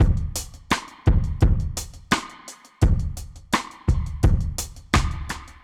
Index of /musicradar/dub-drums-samples/85bpm
Db_DrumsB_Wet_85-03.wav